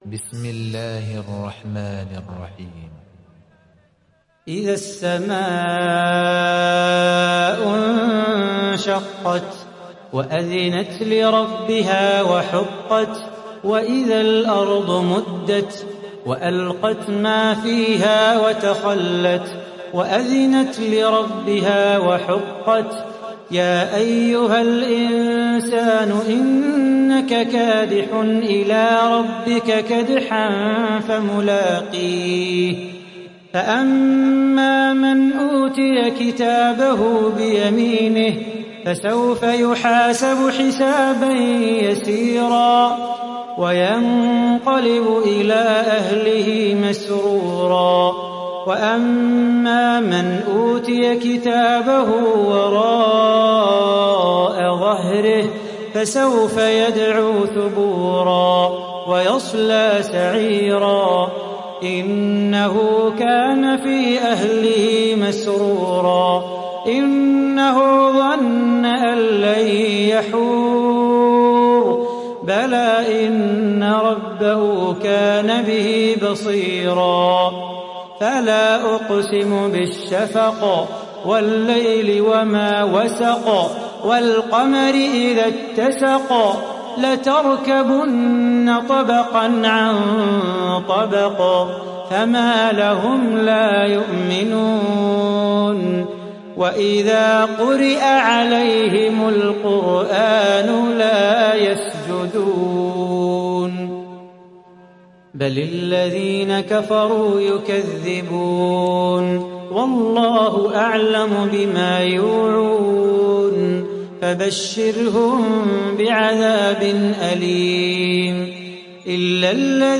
Surah Al Inshiqaq mp3 Download Salah Bukhatir (Riwayat Hafs)